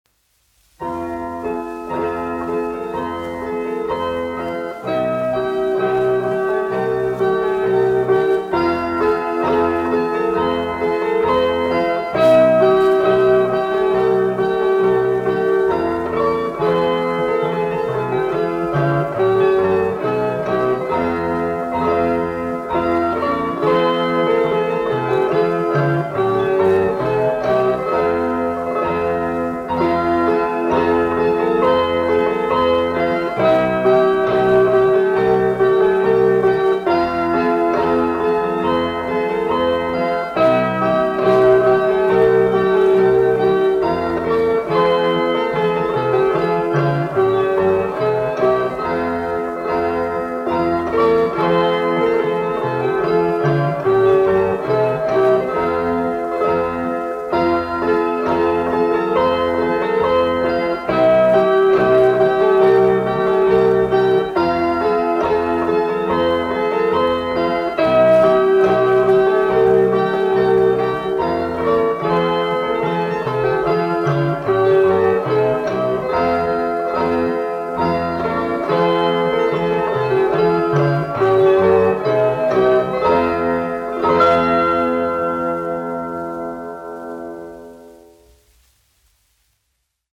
Mugurdancis : latviešu tautas deja
1 skpl. : analogs, 78 apgr/min, mono ; 25 cm
Kokles mūzika
Latvijas vēsturiskie šellaka skaņuplašu ieraksti (Kolekcija)